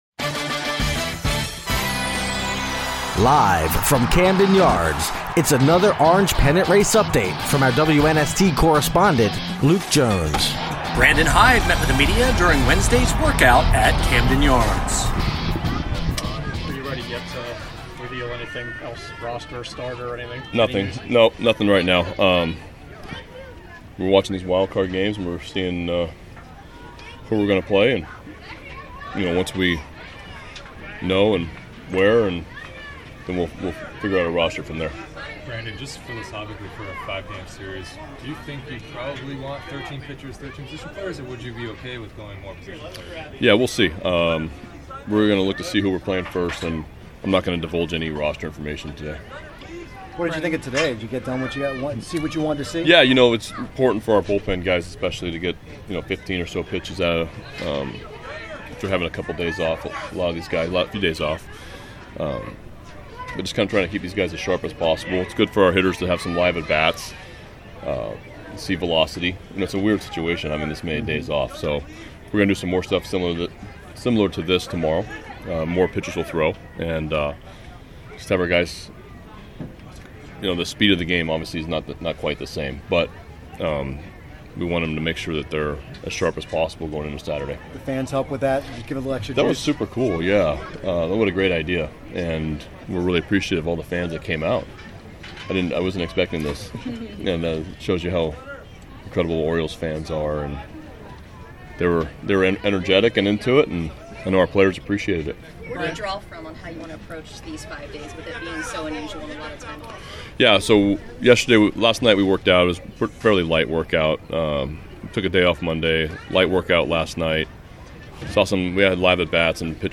Brandon Hyde meets with media following Wednesday workout at Camden Yards